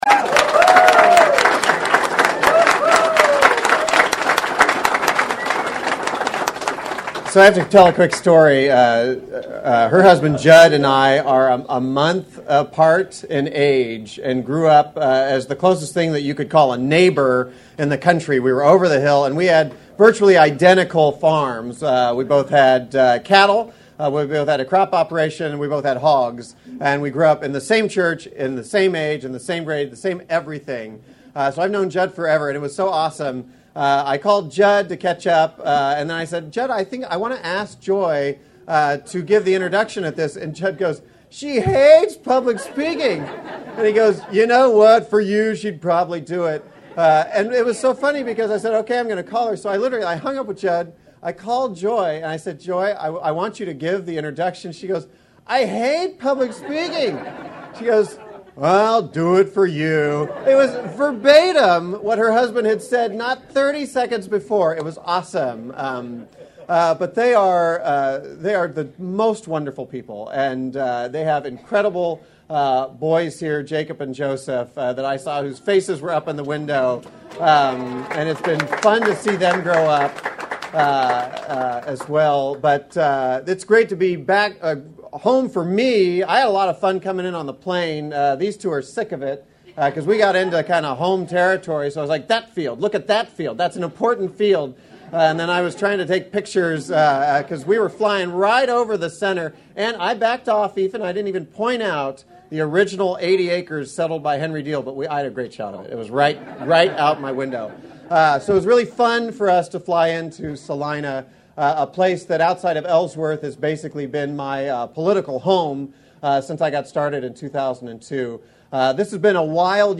It was a homecoming Thursday evening at the Salina Regional Airport when Democrat Kansas Gubernatorial Candidate Josh Svaty landed to introduce his running mate Katrina Lewison.
An enthusiastic crowd of family, friends, and well-wishers greeted the duo as they arrived.
Before introducing Lewison, Svaty spoke to the crowd.